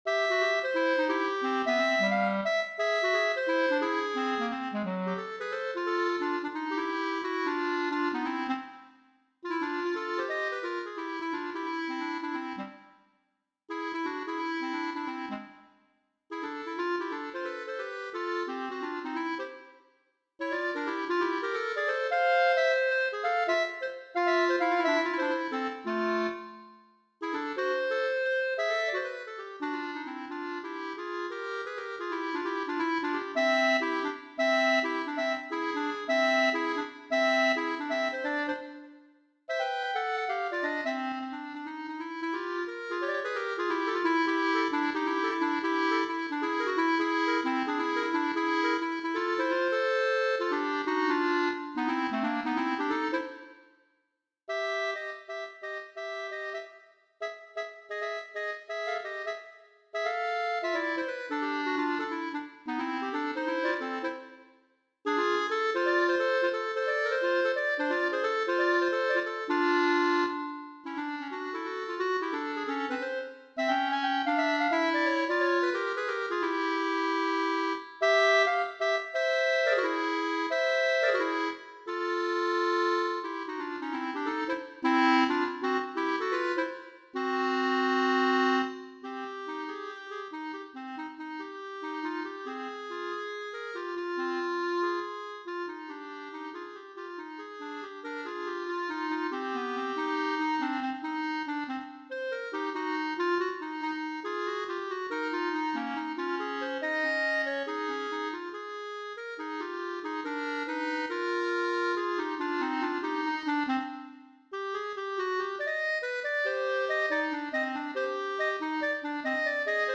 Gattung: Für 2 Klarinetten
Besetzung: Instrumentalnoten für Klarinette